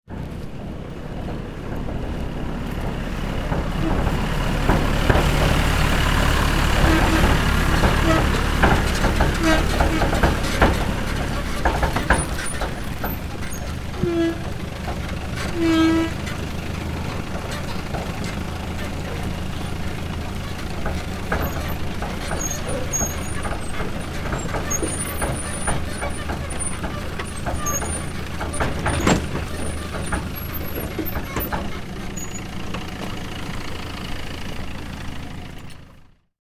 دانلود آهنگ تراکتور 11 از افکت صوتی حمل و نقل
جلوه های صوتی
دانلود صدای تراکتور 11 از ساعد نیوز با لینک مستقیم و کیفیت بالا